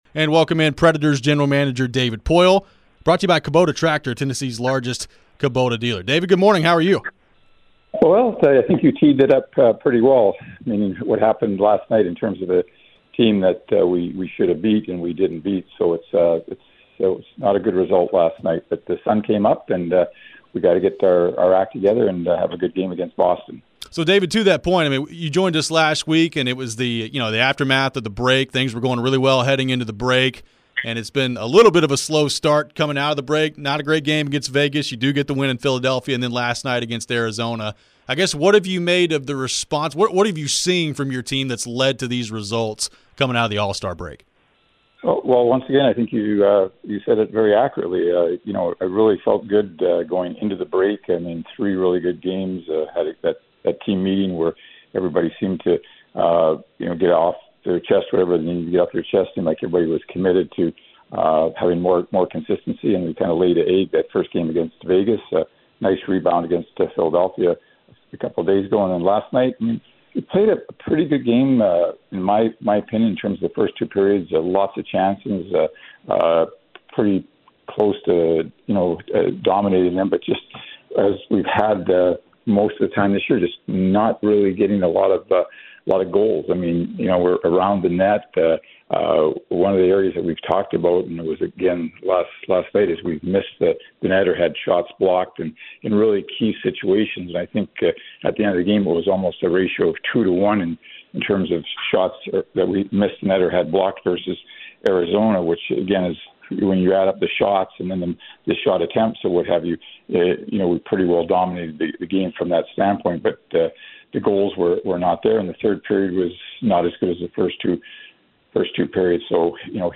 David Poile Interview